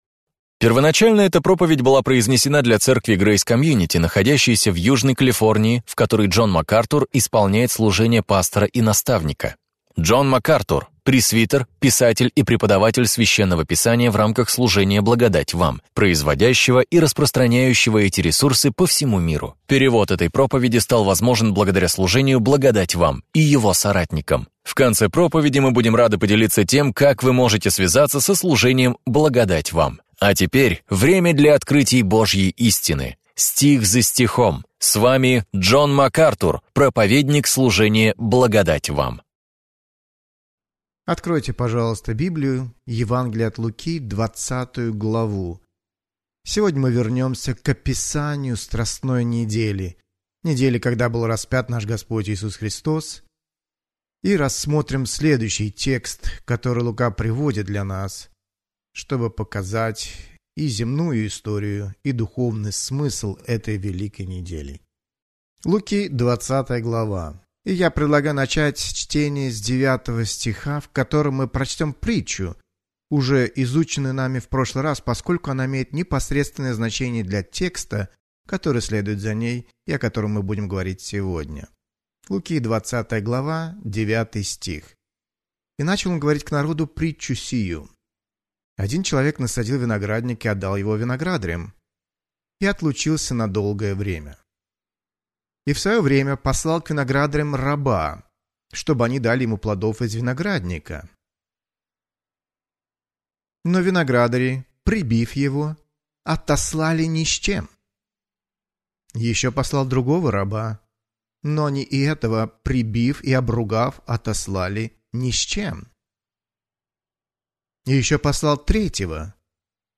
Об этом и пойдет речь в проповеди Джона Макартура «Как общаться с еретиком». Вы увидите изнанку одного из острых конфликтов Христа с набожными врагами истины и Евангелия.